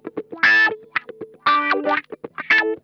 Index of /90_sSampleCDs/Zero G - Funk Guitar/Partition B/VOLUME 021
THROATWAH 3.wav